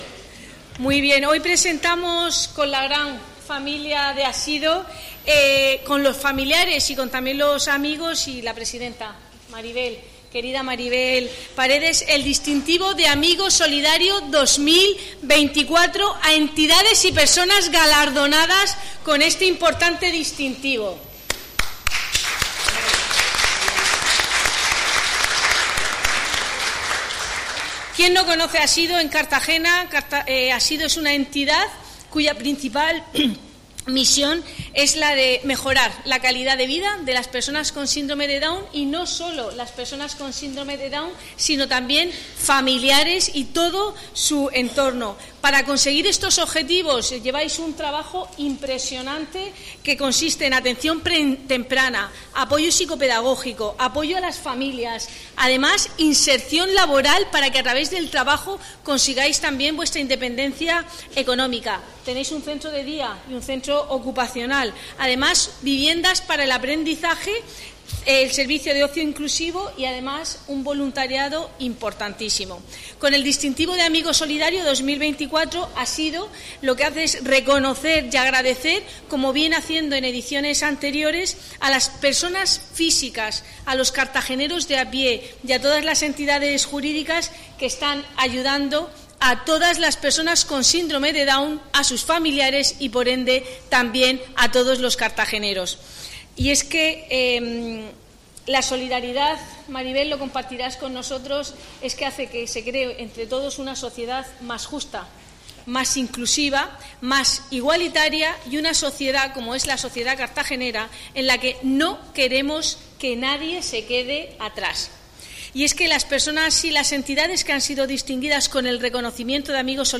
A este emotivo acto de entrega de galardones han acudido la alcaldesa Noelia Arroyo, la concejala de Política Social, Cristina Mora, así como decenas de familias y amigos de la asociación.